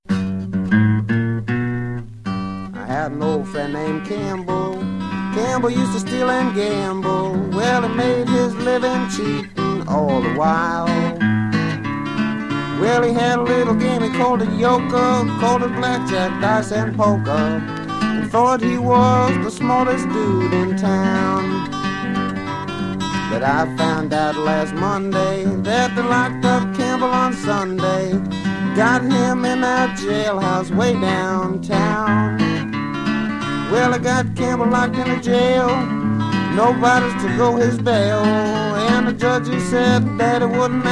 Blues, Country Blues, Ragtime　USA　12inchレコード　33rpm　Stereo